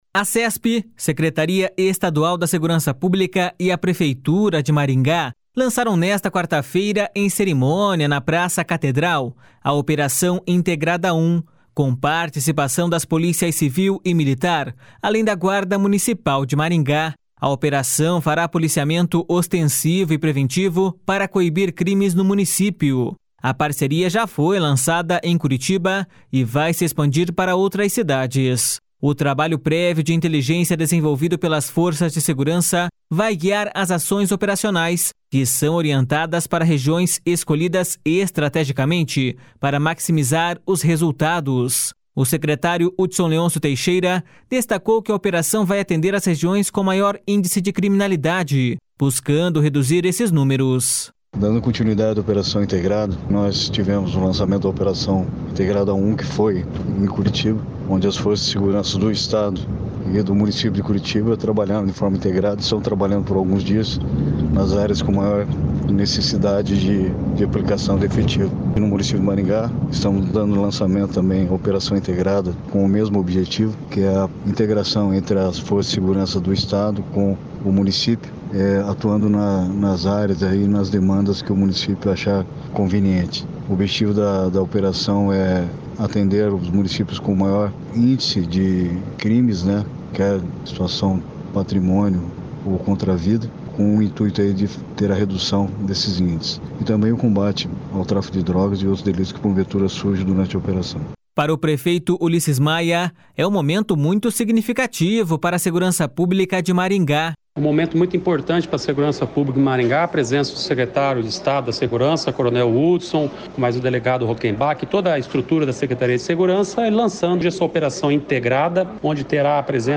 O secretário Hudson Leôncio Teixeira destacou que a operação vai atender as regiões com maior índice de criminalidade, buscando reduzir esses números.// SONORA HUDSON LEÔNCIO TEIXEIRA.//
Para o prefeito Ulisses Maia, é um momento muito significativo para a segurança pública de Maringá.// SONORA ULISSES MAIA.//